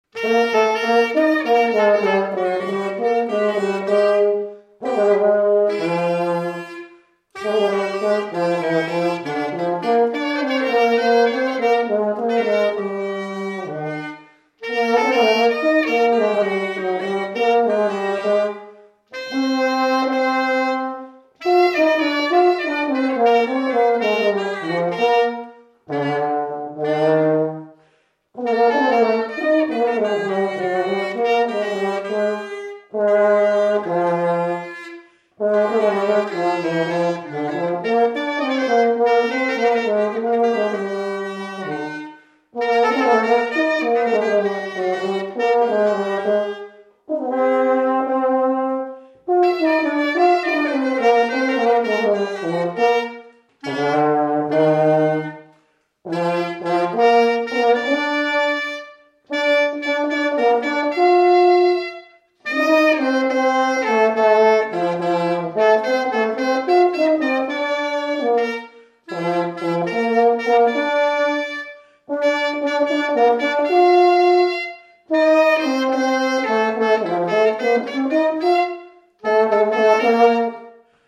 Résumé instrumental Fonction d'après l'analyste danse : pas de quatre
Catégorie Pièce musicale inédite